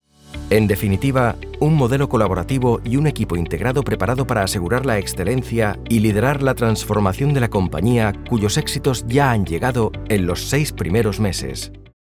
Commercieel, Natuurlijk, Veelzijdig, Vertrouwd, Zakelijk
Explainer